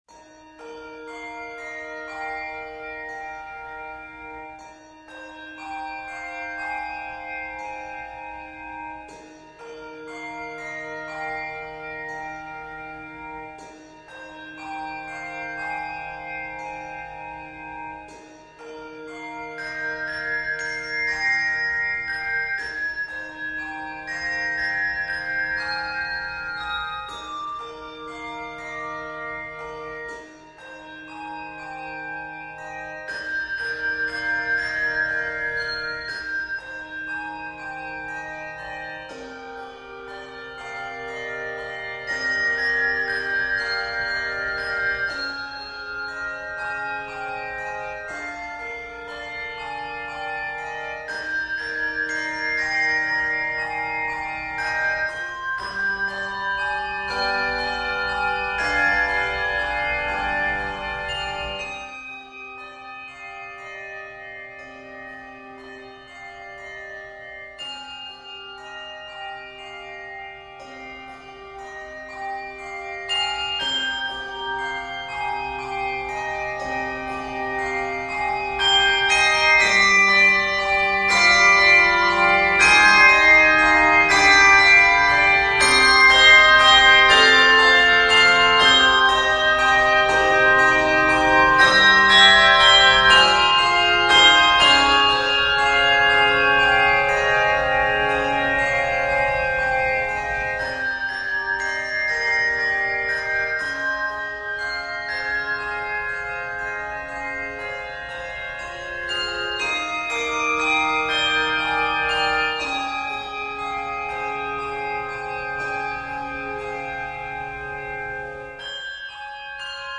With an open and expansive feel, this sensitive work
Octaves: 3-5